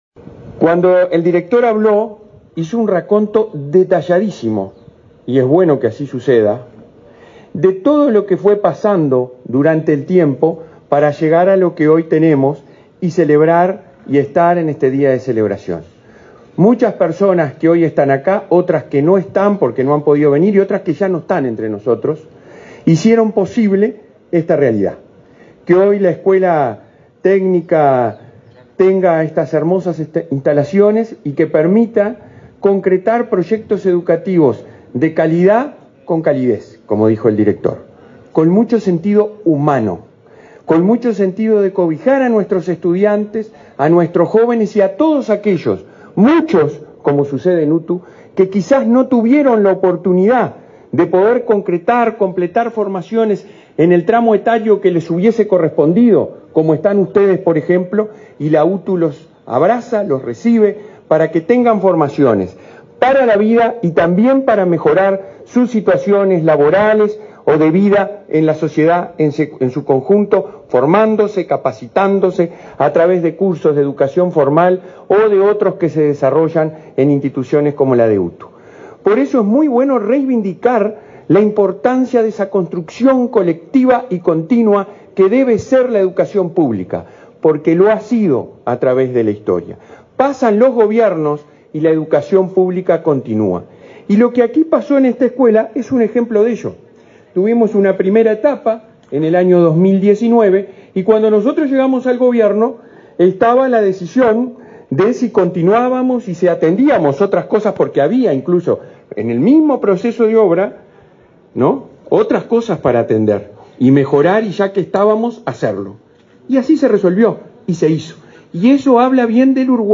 Palabras del presidente de la ANEP, Robert Silva
Palabras del presidente de la ANEP, Robert Silva 20/10/2023 Compartir Facebook X Copiar enlace WhatsApp LinkedIn Este viernes 20, el presidente de la Administración Nacional de Educación Pública (ANEP), Robert Silva, participó en la inauguración de la reforma de la escuela técnica de Nueva Helvecia, en el departamento de Colonia.